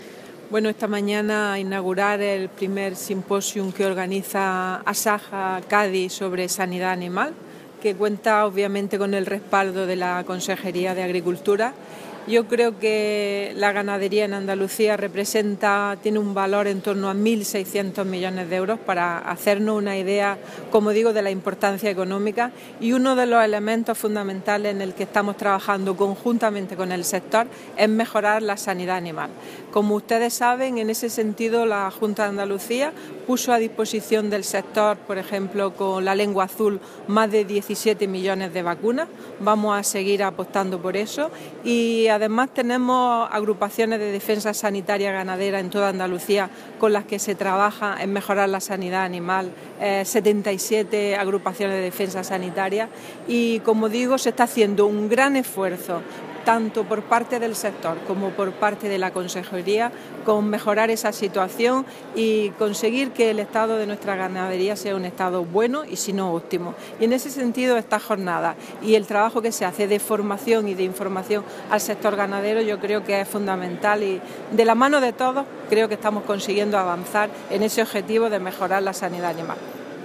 Declaraciones de Carmen Ortiz sobre el I Simposio de Sanidad Animal de Asaja-Cádiz